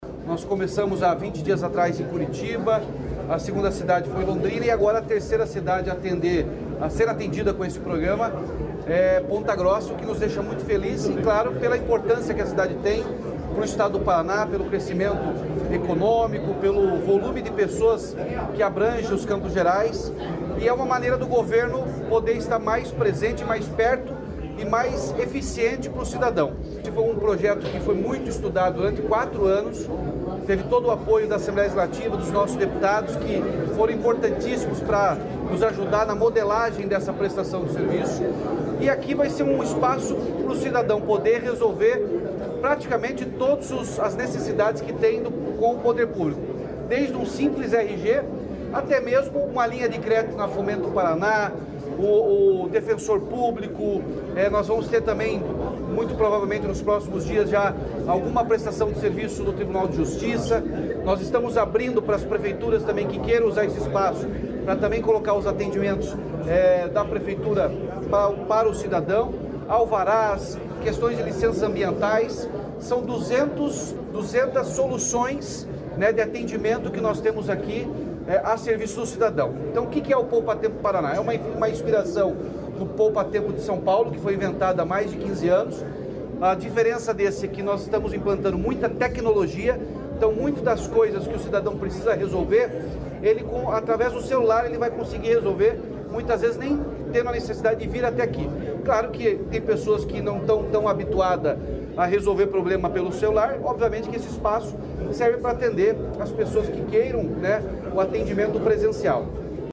Sonora do governador Ratinho Junior sobre a nova unidade do Poupatempo em Ponta Grossa